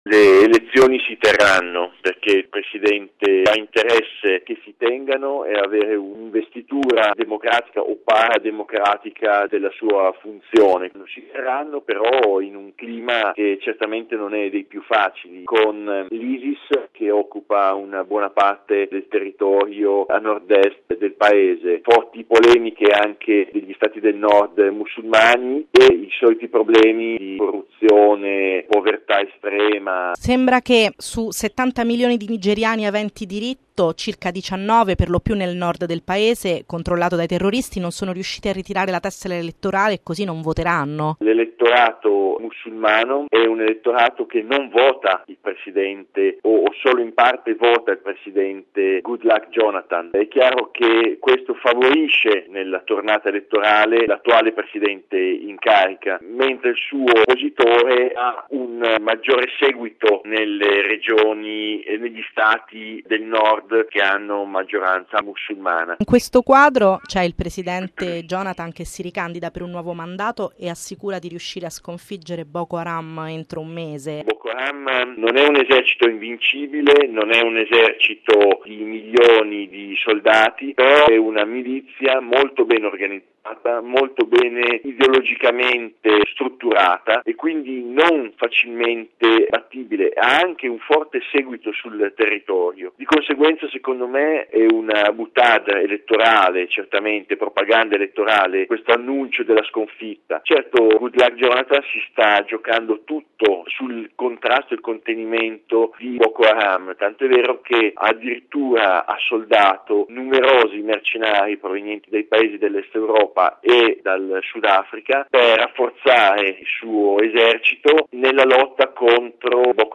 Bollettino Radiogiornale del 20/03/2015